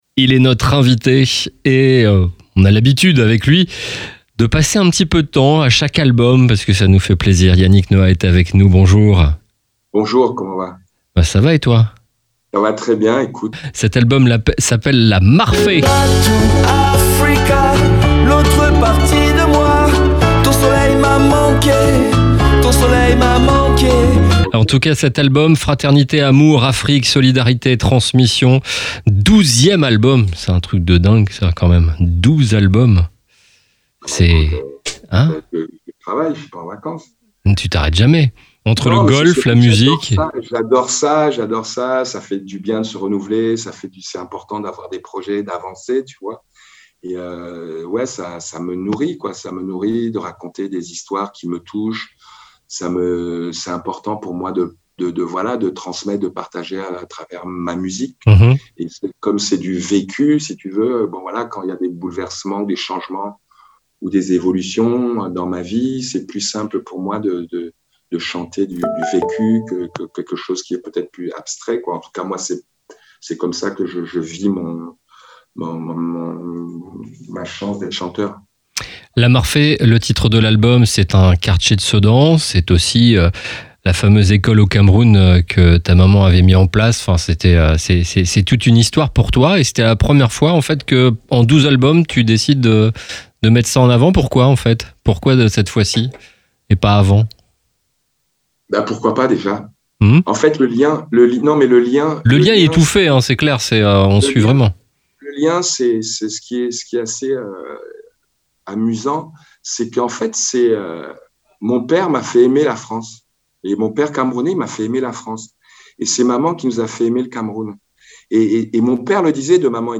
interview exclusive